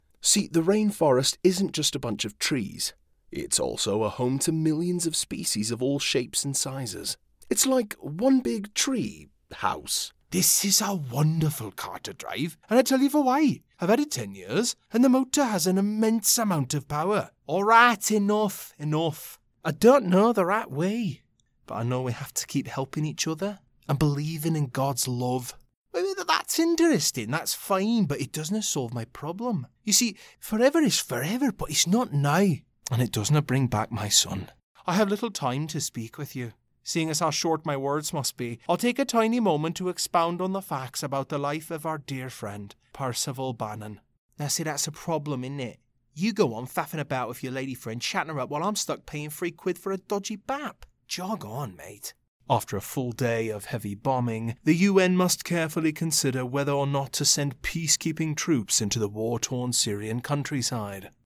UK Dialects↓ Download
Professionally-built, broadcast quality, double-walled LA Vocal Booth.